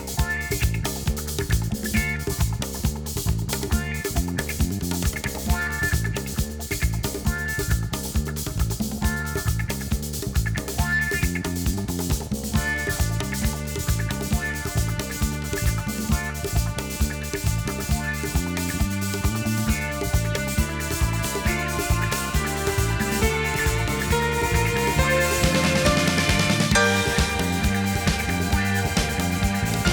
Genre: Instrumental